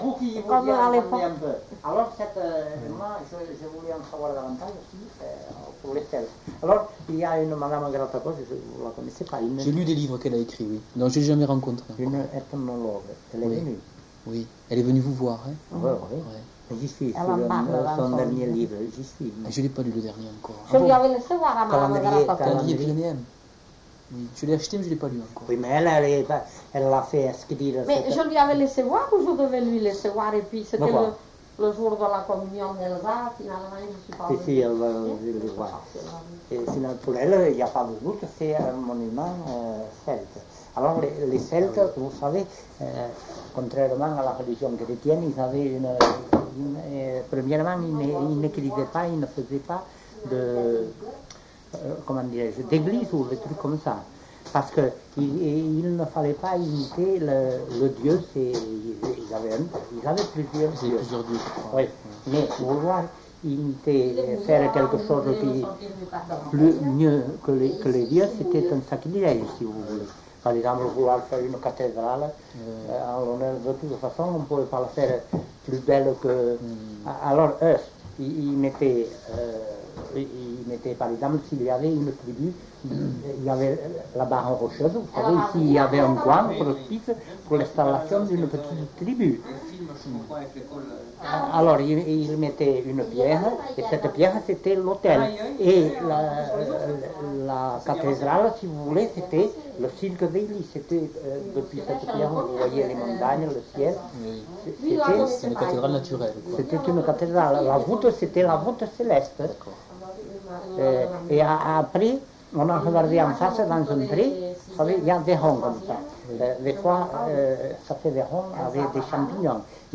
Aire culturelle : Couserans
Lieu : Eylie (lieu-dit)
Genre : témoignage thématique